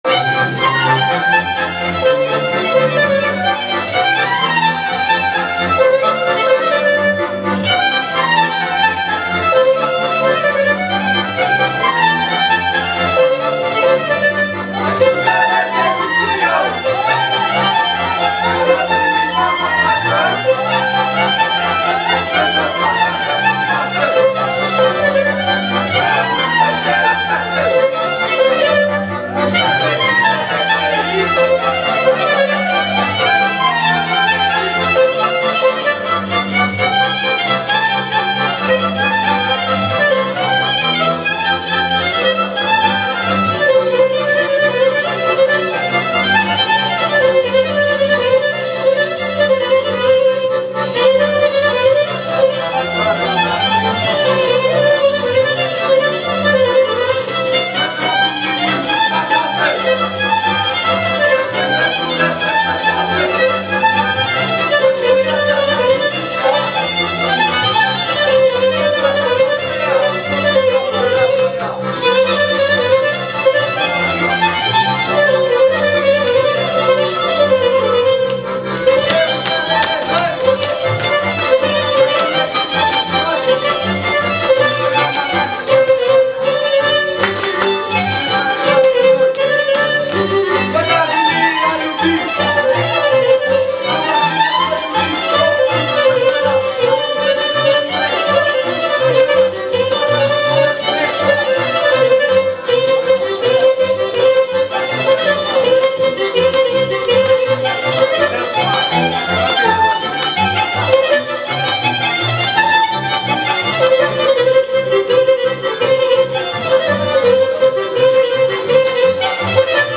Dans_de_pe_Valea_Muresului_-_Ansamblul_Liceului_Pedagogic_Arad.mp3